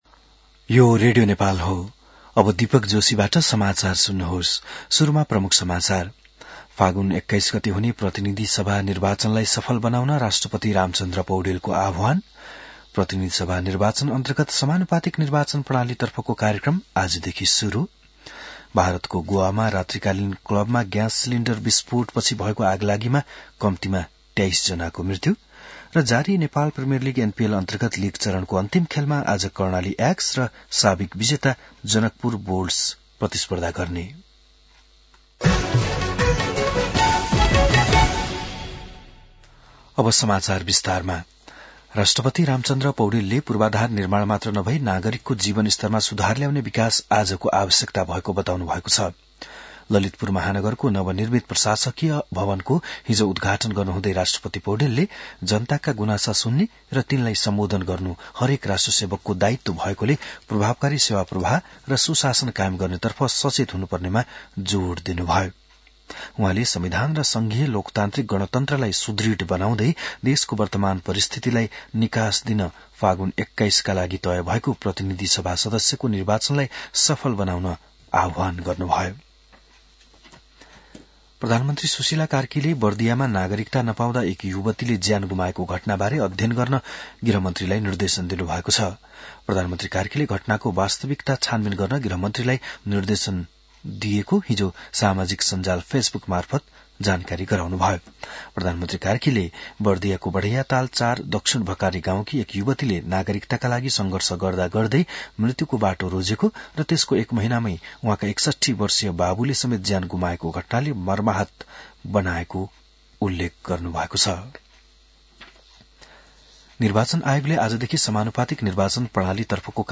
बिहान ९ बजेको नेपाली समाचार : २८ असार , २०८२